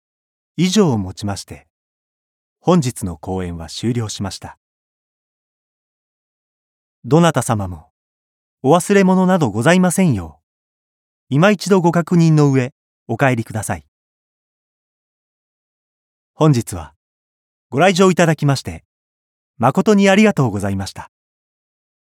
通常版 演技版１ 演技版２
Voice Sample
方言 京都弁